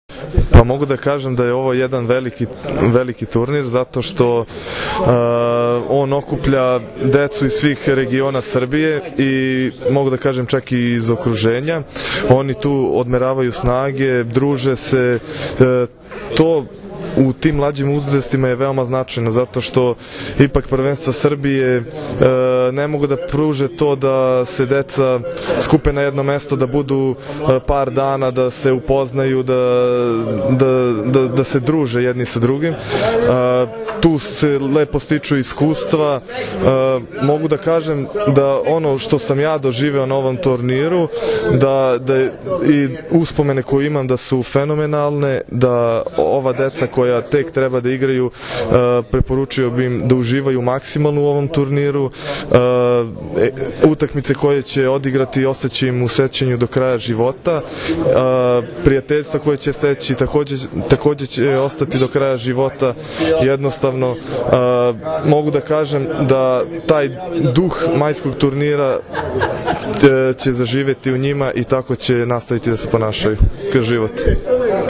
Povodom „Trofeja Beograd 2014.“ – 49. Međunarodnog turnira Gradskih omladinskih reprezentacija, koji će se odigrati od 1. – 3. maja, danas je u sali Gradske uprave Grada Beograda održana konferencija za novinare.